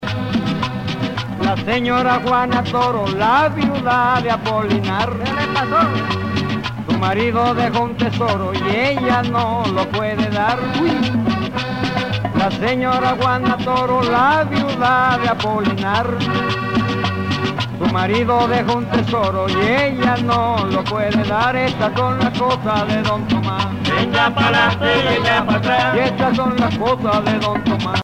danse : porro (Caraïbe colombienne)
Pièce musicale éditée